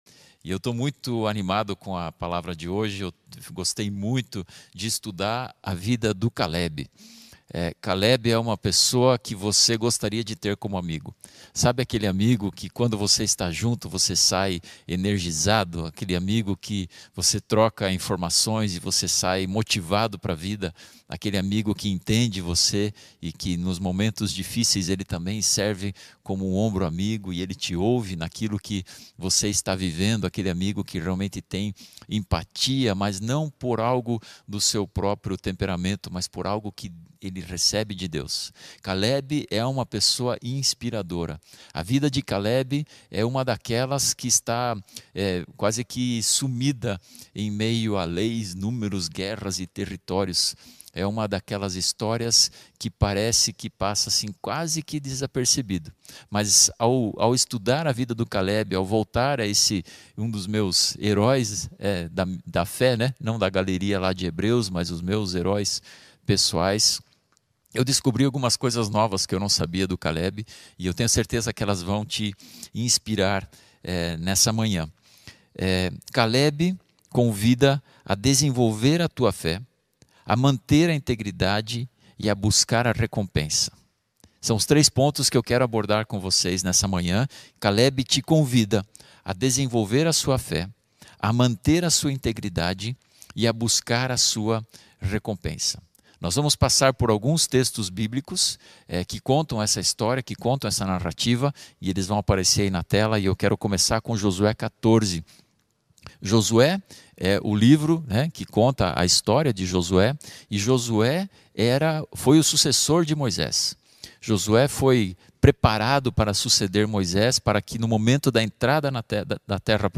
mensagem_de_domingo_27.mp3